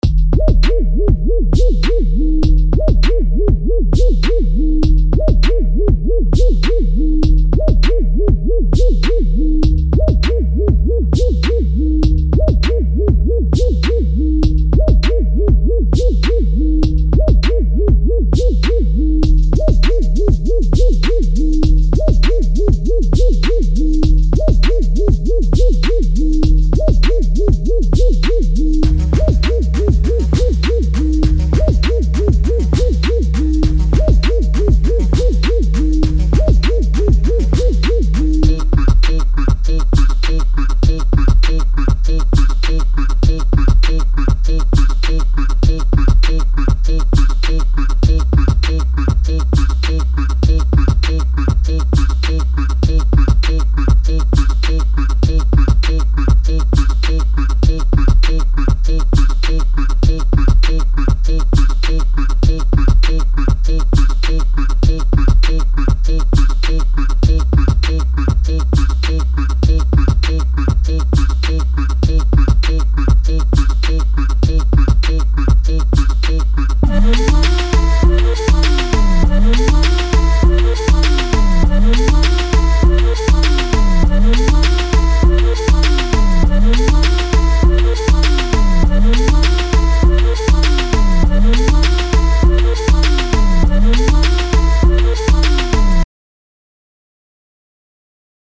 Hip HopTrap